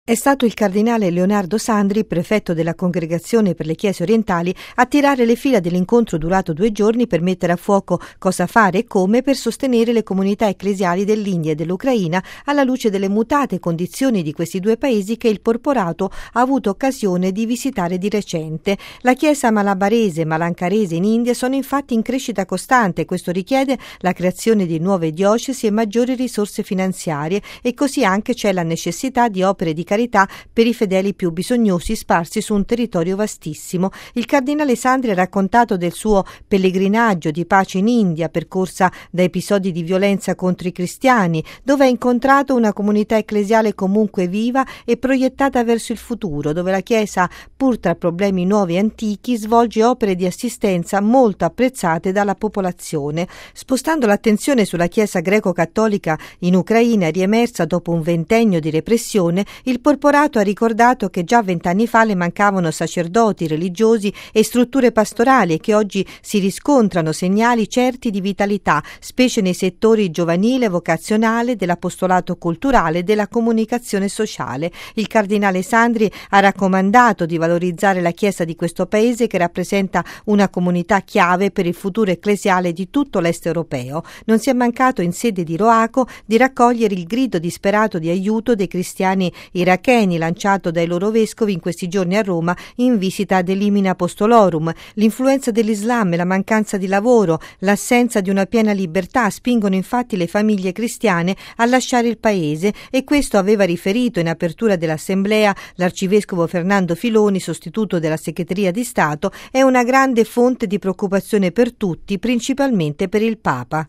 Radio Vaticana - Radiogiornale